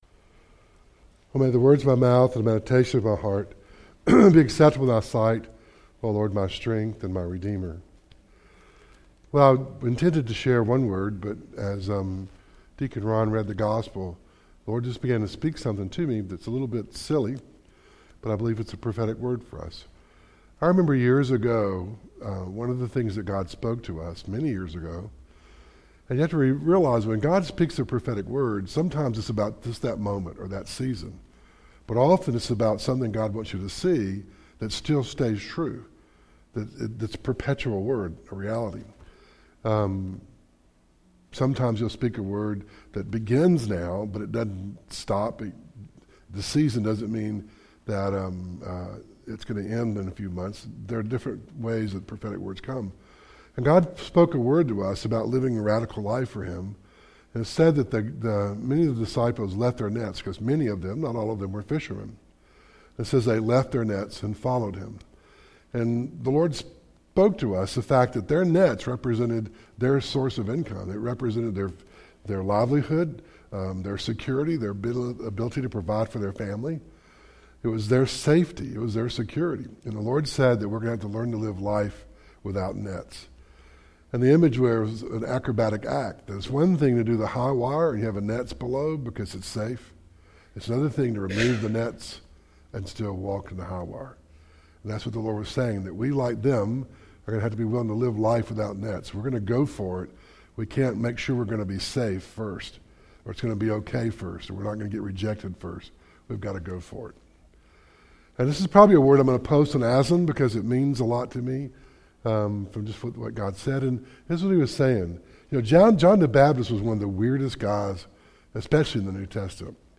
Preacher :